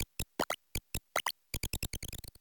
Cri de Famignol Famille de Quatre dans Pokémon Écarlate et Violet.
Cri_0925_Quatre_EV.ogg